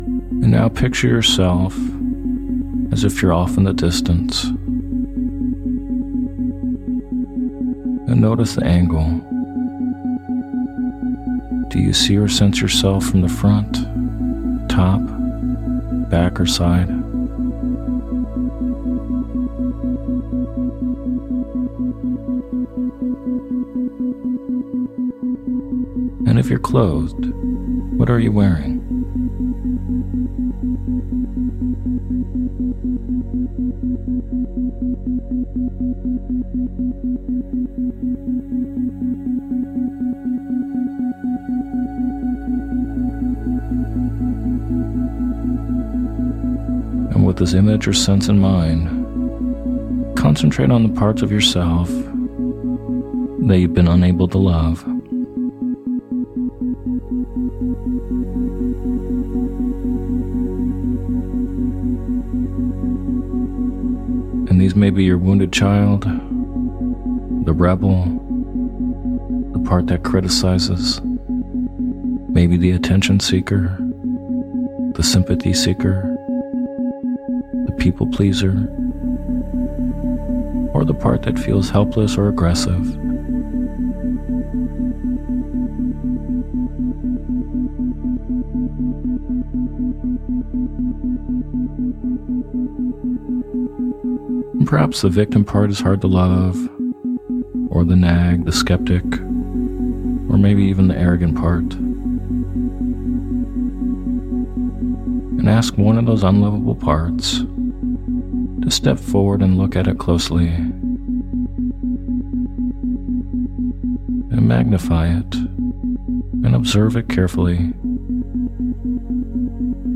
Sleep Hypnosis For Loving Parts Of The Self With Isochronic Tones